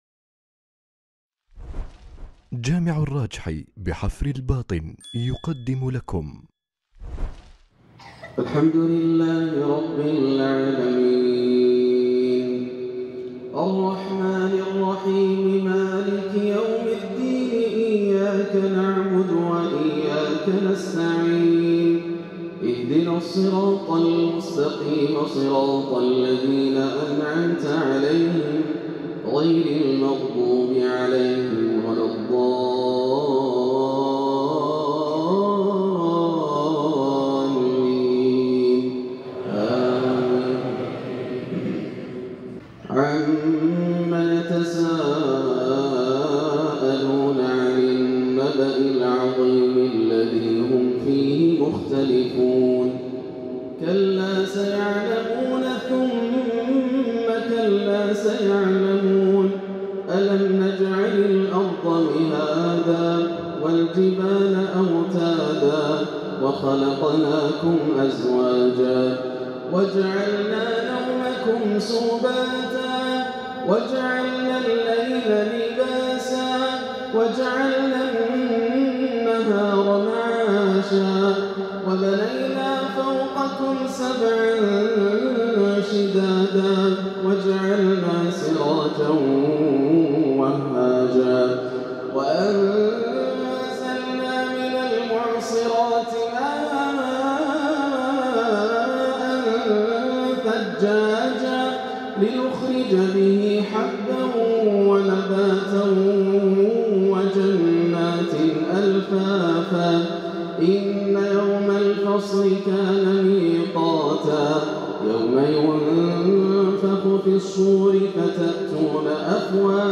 مغرب الاحد 6-8-1439هـ سورتي النبأ و الانفطار جامع الراجحي بحفر الباطن > عام 1439 > الفروض - تلاوات ياسر الدوسري